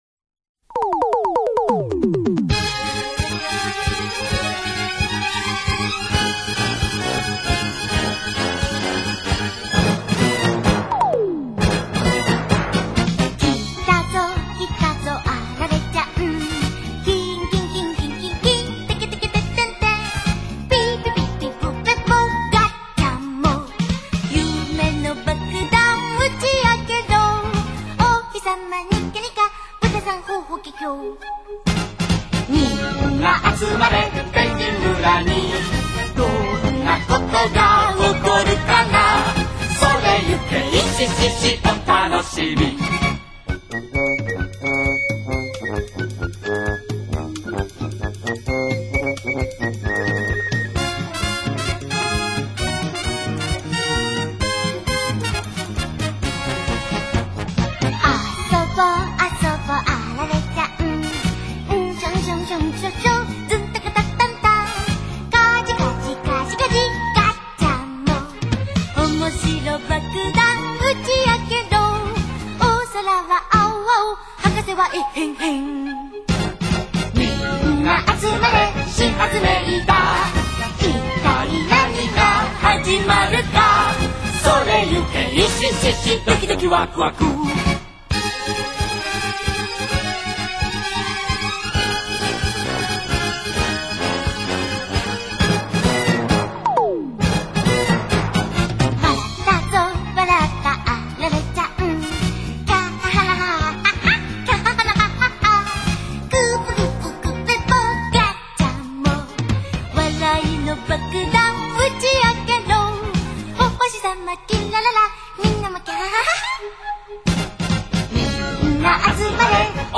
Opening Theme Song